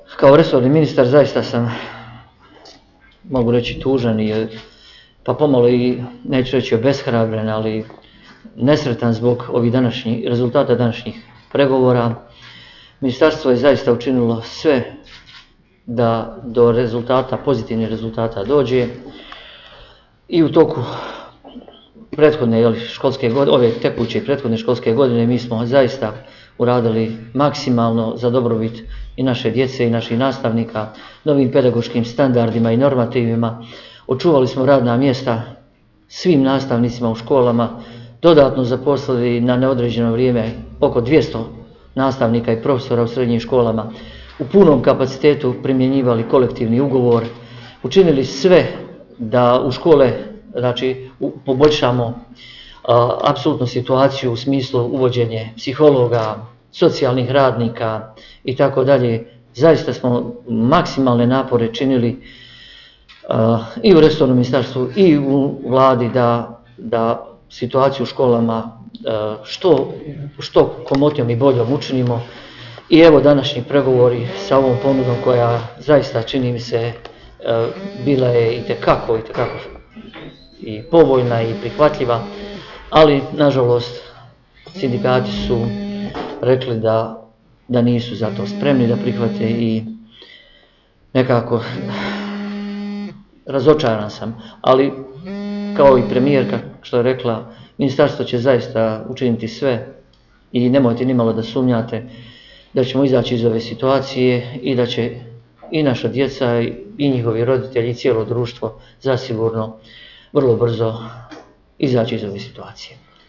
Cjeloviti zvučni zapisi s konferencije za novine na kojoj su se, uz predsjednicu Buhač, javnosti obratili i ministri Velagić i Šuta, dostupni su u privitku.